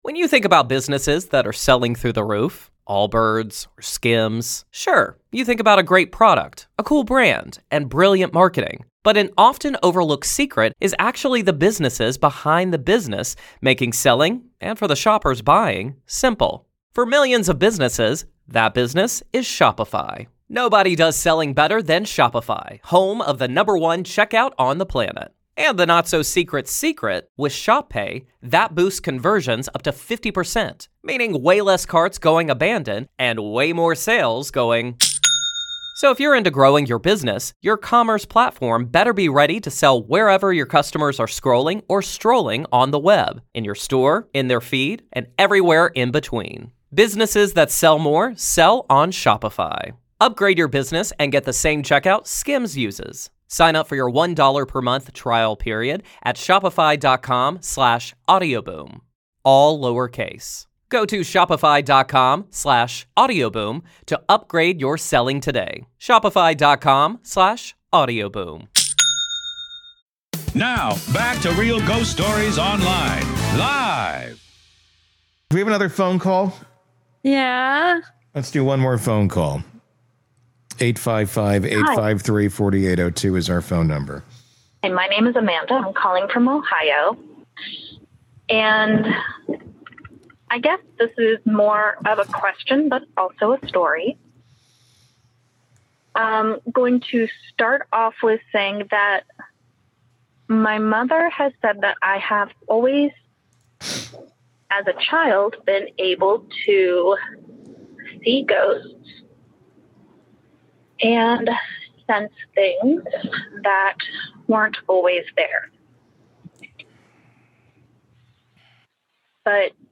Real Ghost Stories LIVE!